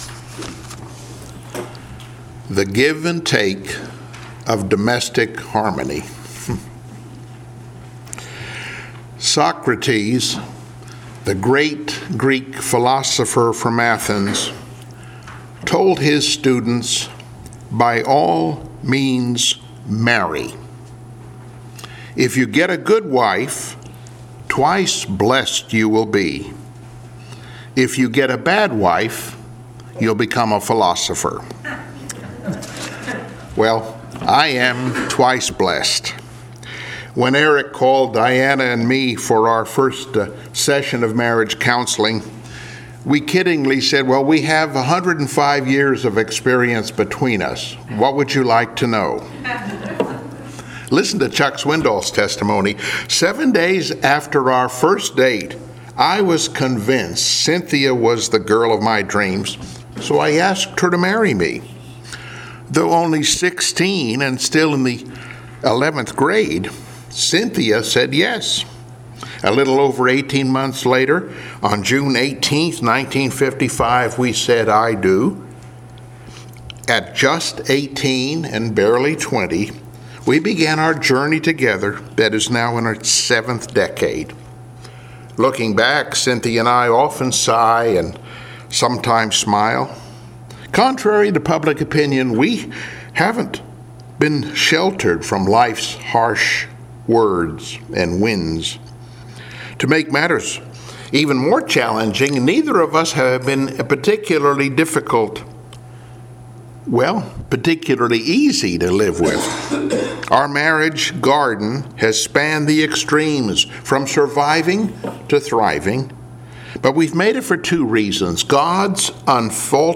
I Peter 3:1-7 Service Type: Sunday Morning Worship « “Pressing On